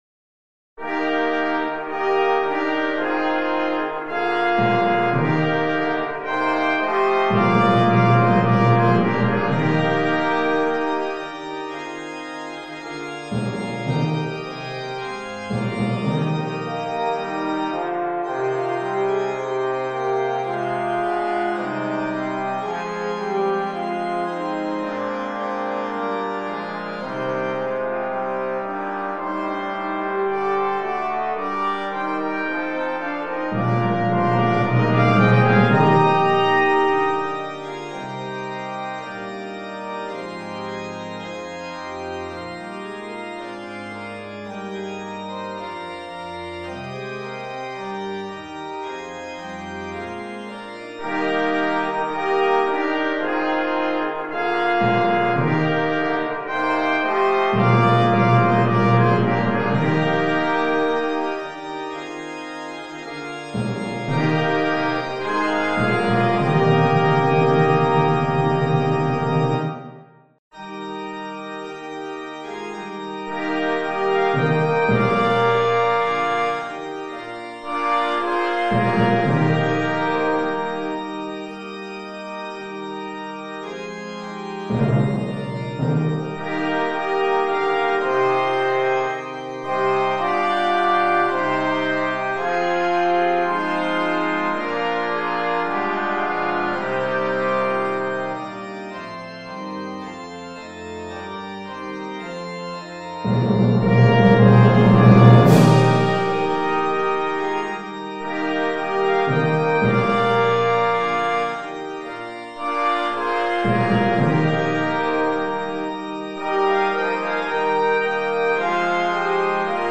Voicing: Congregation